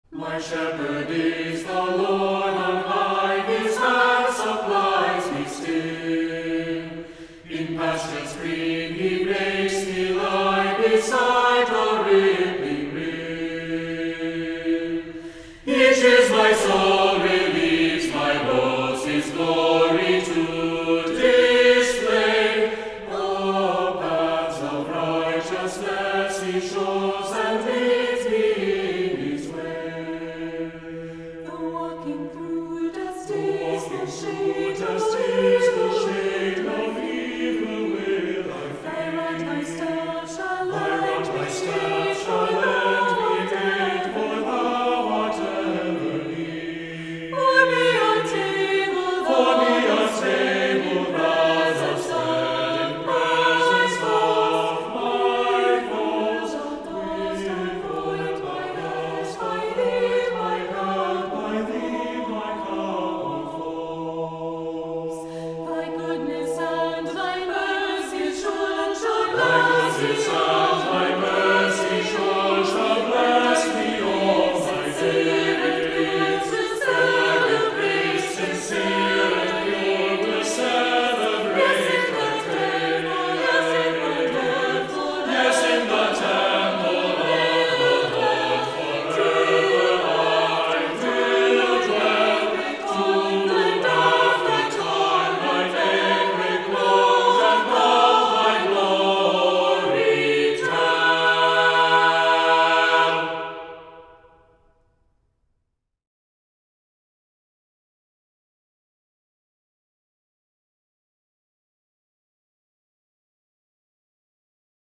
Voicing: Three-part equal; Three-part mixed